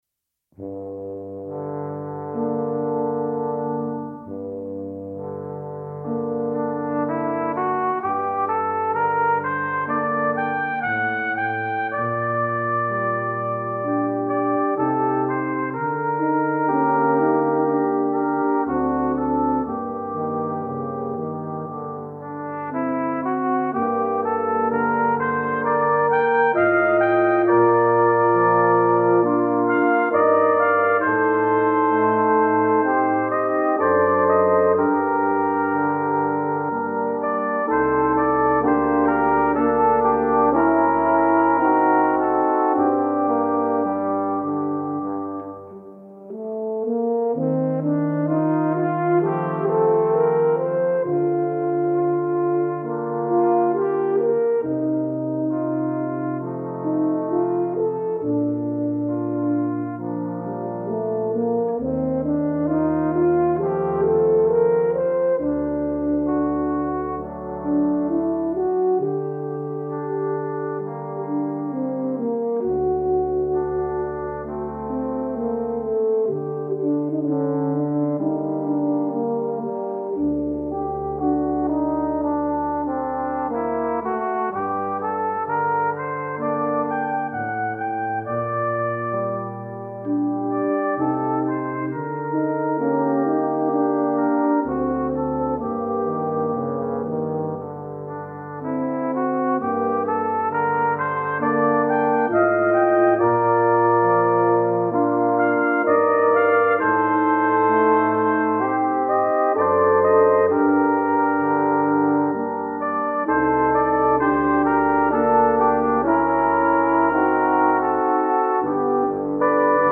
П.И.Чайковский. Баркарола. Квинтет медных духовых